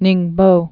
(nĭngbō) also Ning·po (-pō)